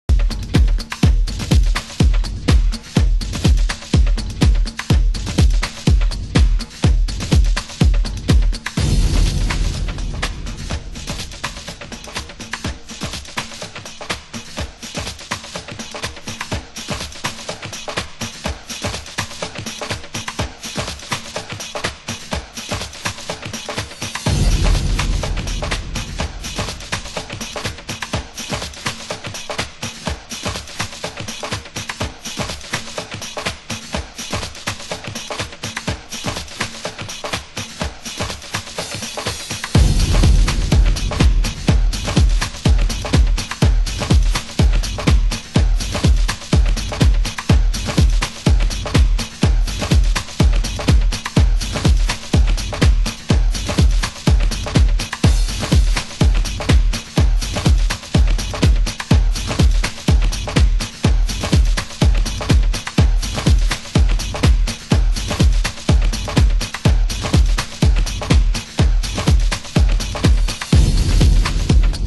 盤質：A1に中盤に傷ノイズ 有/B1に不純物による小さなノイズ 有/少しチリパチノイズ有